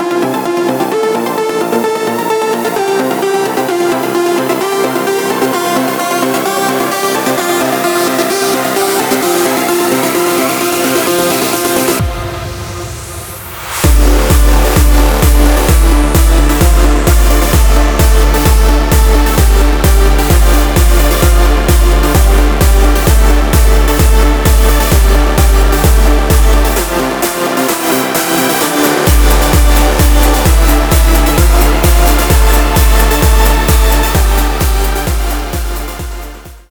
• Качество: 320, Stereo
громкие
Electronic
EDM
без слов
Trance
Жанр: Транс